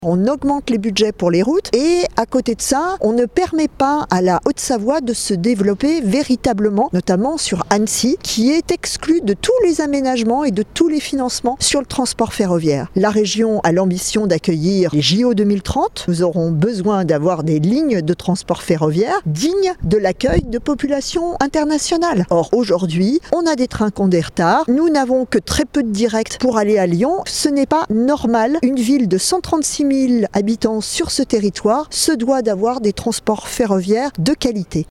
Fabienne Grébert est conseillère régionale "Les Ecologistes" de Haute-Savoie :